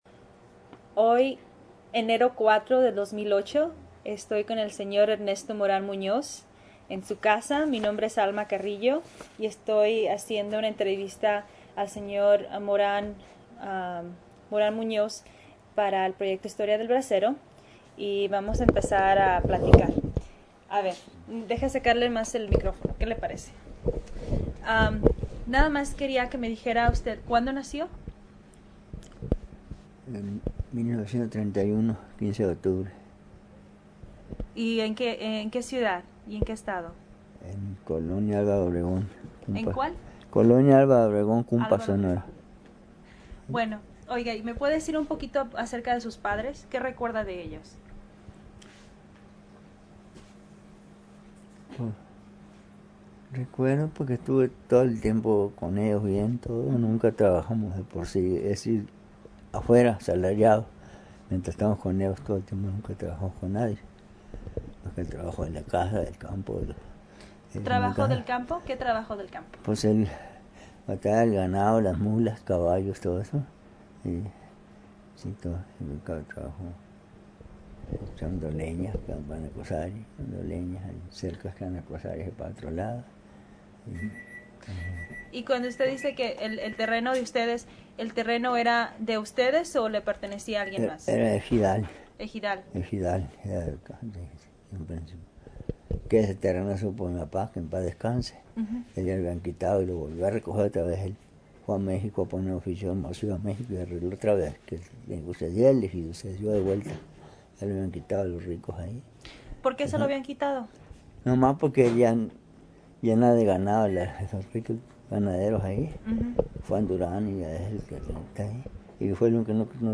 Location Tucson, Arizona